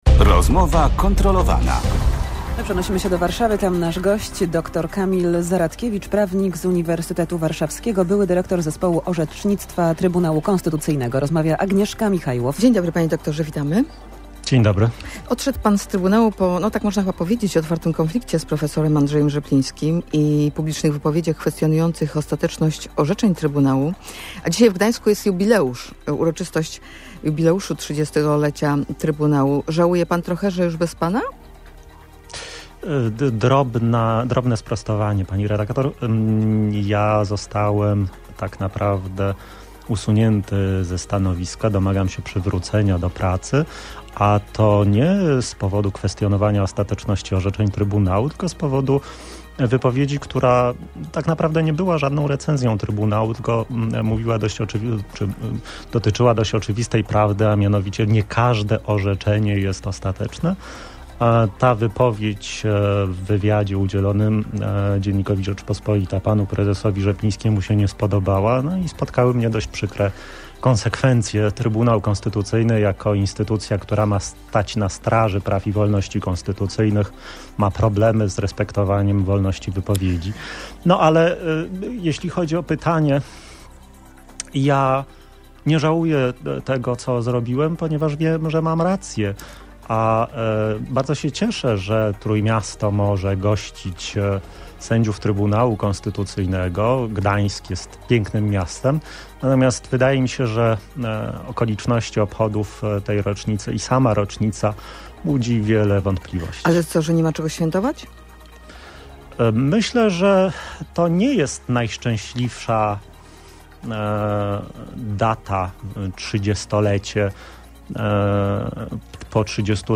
Były dyrektor Zespołu Orzecznictwa i Studiów TK komentuje rozpoczynające się w Gdańsku obchody 30-lecia powstania Trybunału Konstytucyjnego w audycji Rozmowa Kontrolowana w Radiu Gdańsk.
Gość Radia Gdańsk podkreśla, że cieszy się, iż Gdańsk może gościć sędziów TK. Jego wątpliwości budzą okoliczności obchodów rocznicy i sama rocznica.